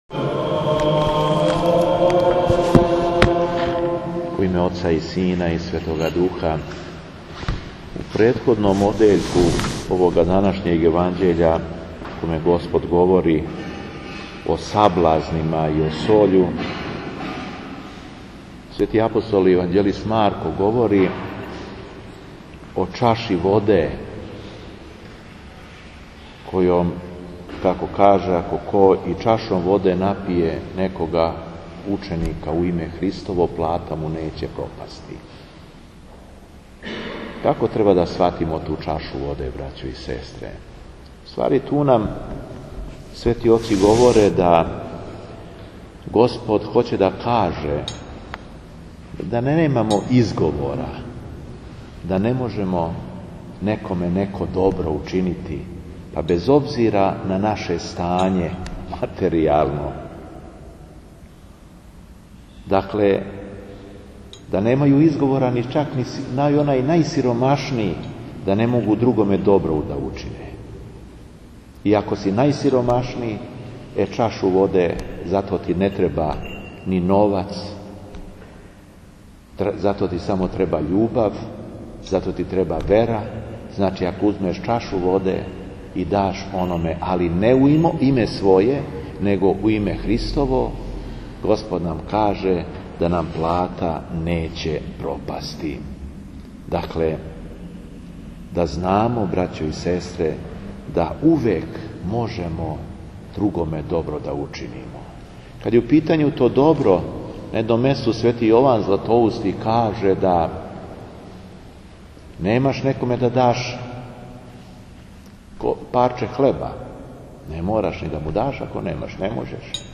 ВЛАДИКА ЈОВАН – НИЈЕДНО ДОБРО КОЈЕ ЧОВЕК УЧИНИ НЕЋЕ ПРОПАСТИ У понедељак 31. децембра Епископ шумадијски Господин Јован служио је Свету Литургију у храму Светог Саве на Аеродрому.
Беседа епископа шумадијског Г. Јована